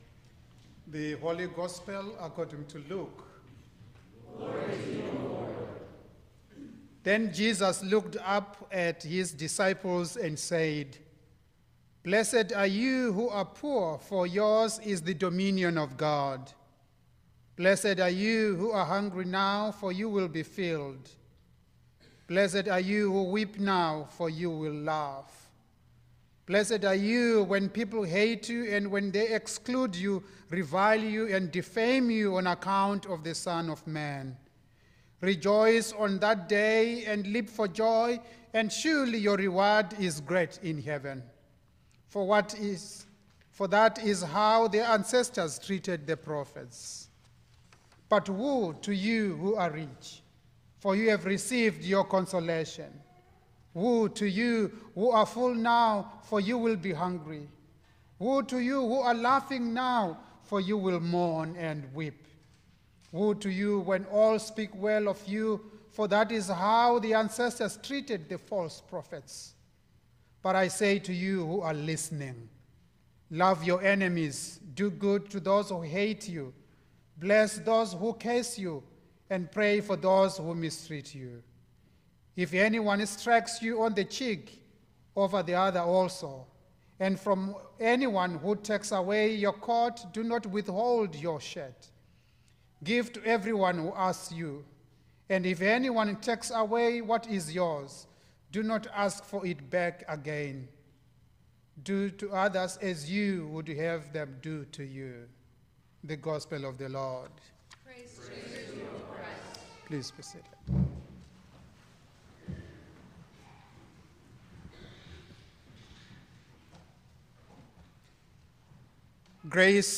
Sermon for All Saints Sunday 2025